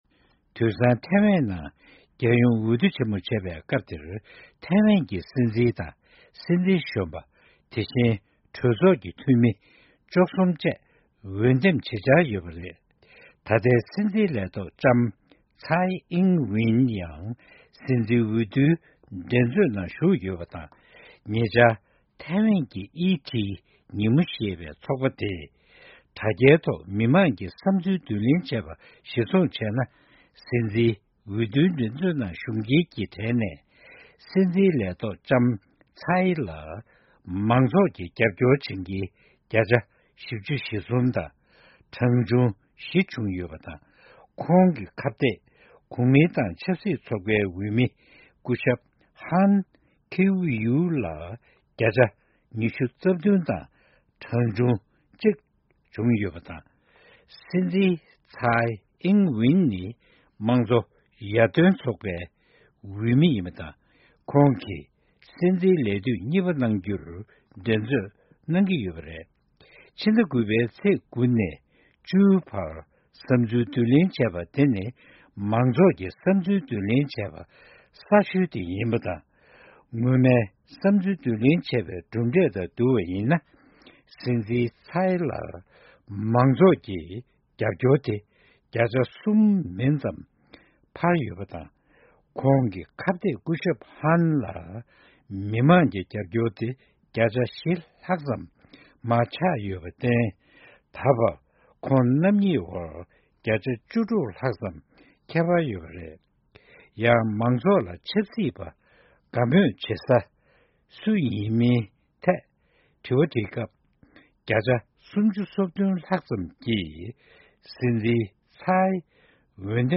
ཕྱོགས་བསྒྲིགས་དང་སྙན་སྒྲོན་ཞུ་ཡི་རེད།